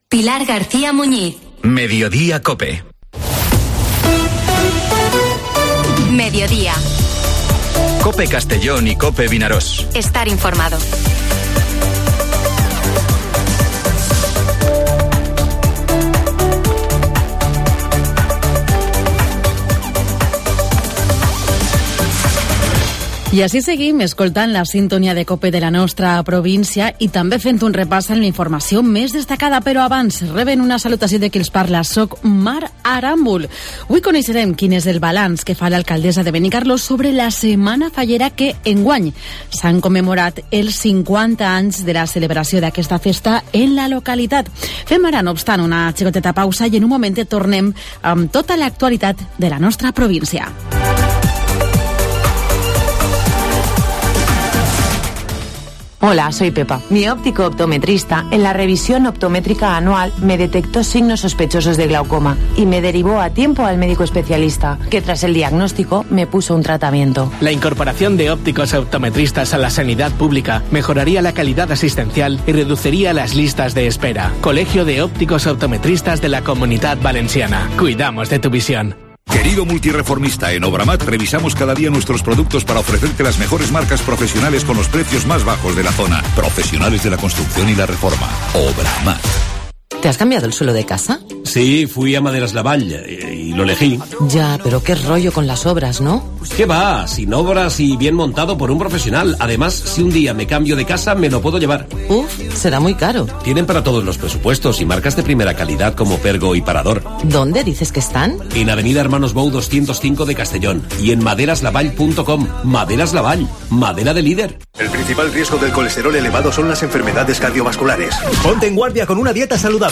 Entrevista con motivo del Día Mundial del Síndrome de Down y balance de la semana fallera en Benicarló, en su 50 aniversario de la celebración de las Fallas.